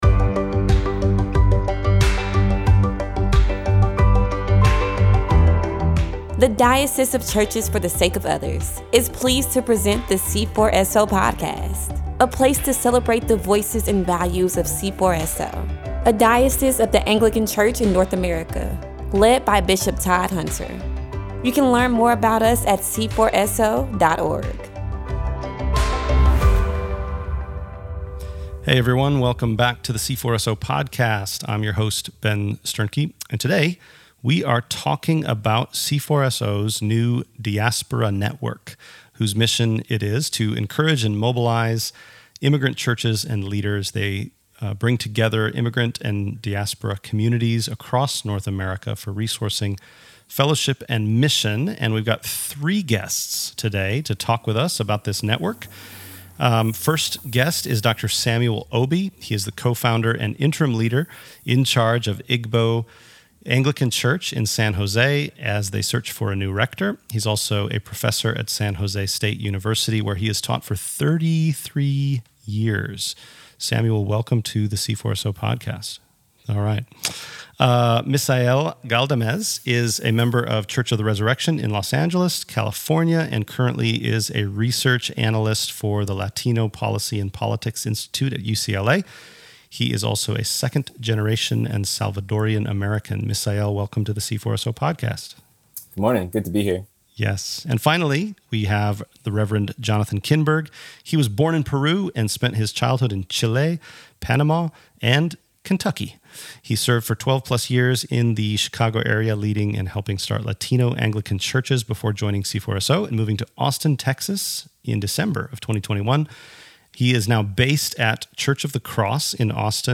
C4SO's Diaspora Network seeks to encourage and mobilize immigrant churches and leaders by bringing together immigrant and diaspora communities across North America for resourcing, fellowship and mission. Today we talk with three guests about the Diaspora Network